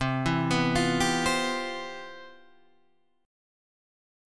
C Major 11th